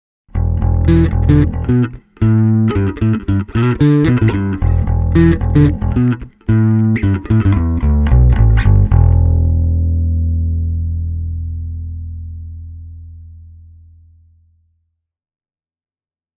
Pelkällä säröllä varustettuna bassosignaalilla on suhteellisen kevyt basso ja alkuperäissignaalia kapeampi dynamiikka:
Lisäämällä särösignaaliin myös puhdasta signaalia on lopputulos huomattavasti dynaamisempi ja basson alkuperäinen potku säilyy paremmin:
drive-clean.mp3